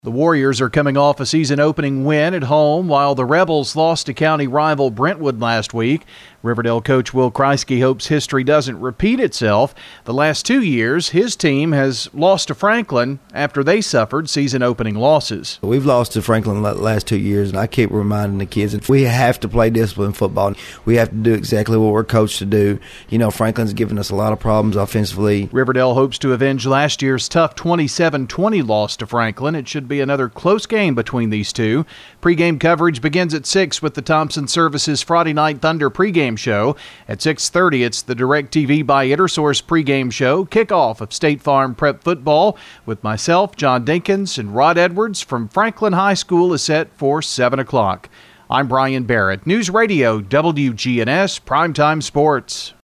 previews tonight's radio game...